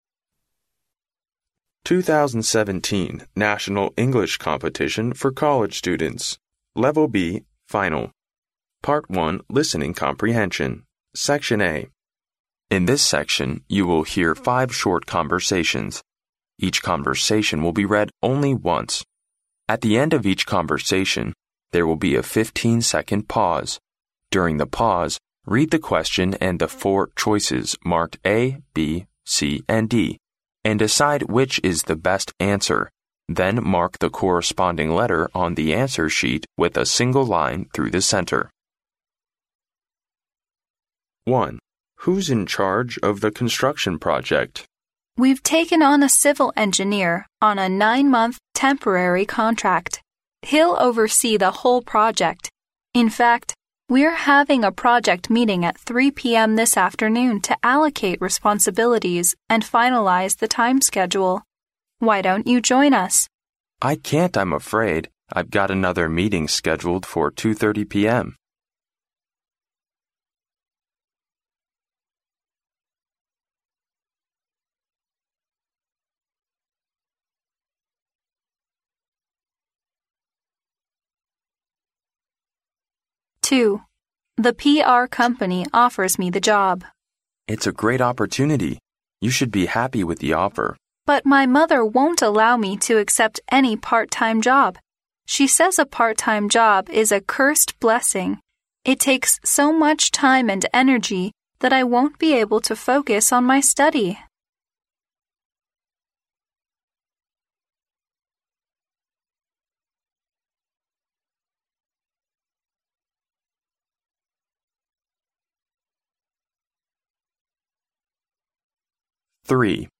In this section, you will hear five short conversations. Each conversation will be read only once. At the end of each conversation, there will be a fifteen-second pause.